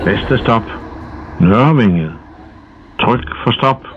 Højttalerudkald Metro og Letbane
Jørgen Leth udkald.